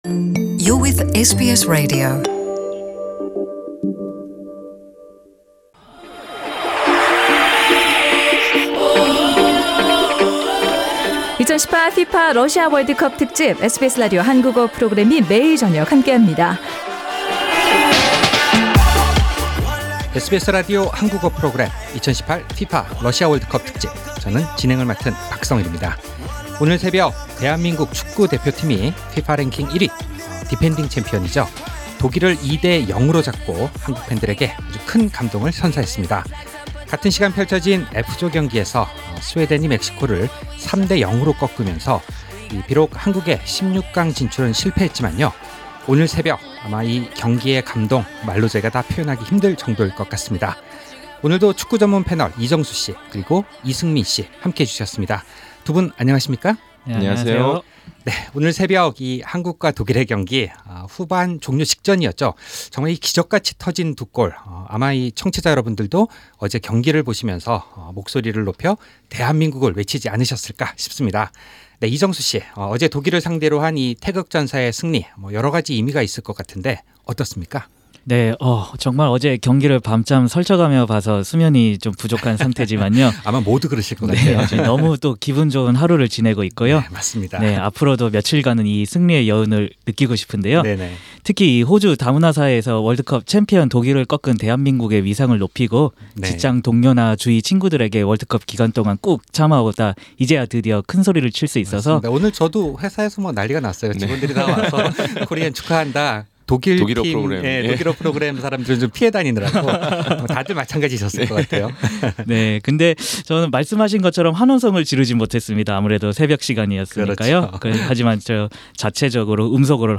During tonight program, we will focus on analyzing the ‘South Korea v Defending champions Germany’ match. The World Cup panel of two in Melbourne